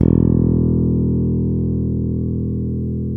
Index of /90_sSampleCDs/Roland L-CD701/BS _Jazz Bass/BS _Ch.Fretless